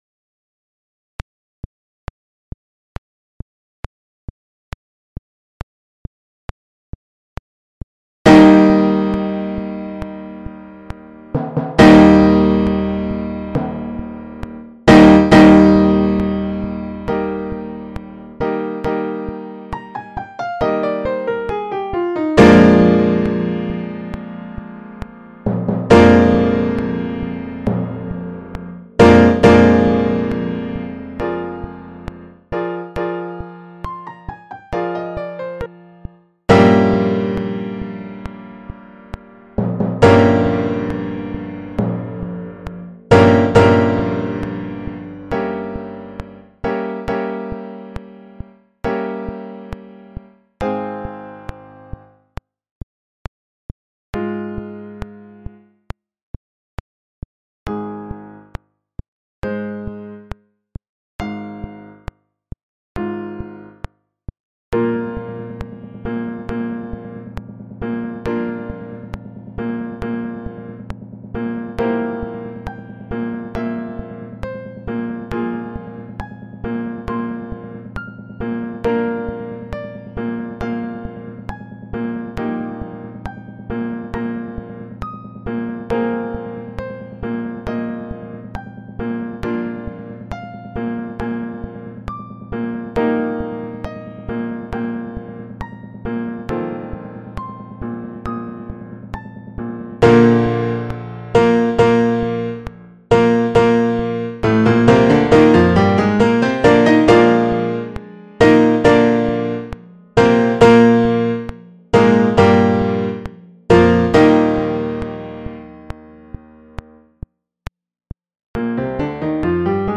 MIDI Practice Tracks:
These are Midi renderings with the first violin part removed. I really dislike the midi orchestral sounds, so I changed them all to the piano patch.
Eighth Note = 68